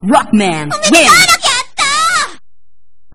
Reason: Should be a direct audio rip rather than an in-game recording.